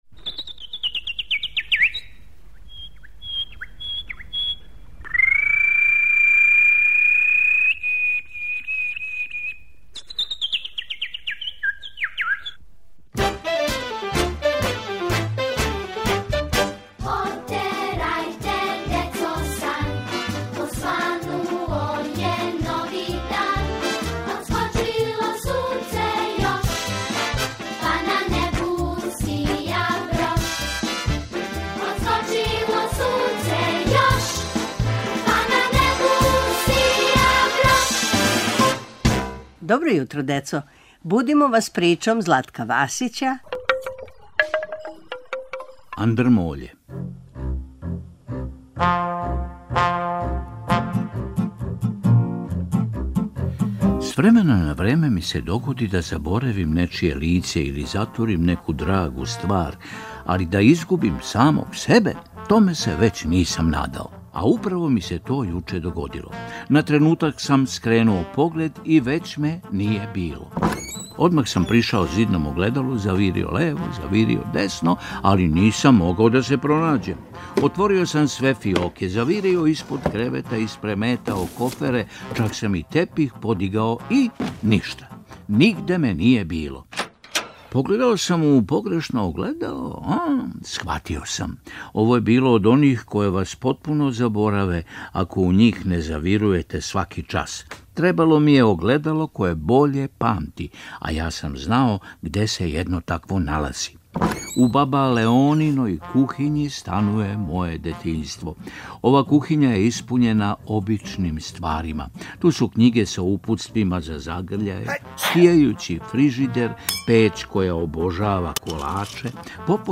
Будимо вас причом Златка Васића "Андрмоље"